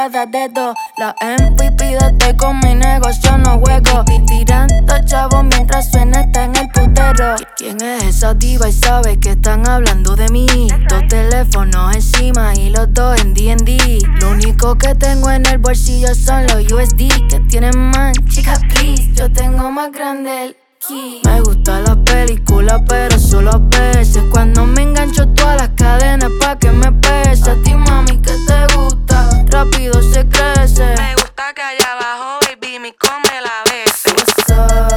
Urbano latino Latin Electronic Dubstep
Жанр: Латино / Электроника / Дабстеп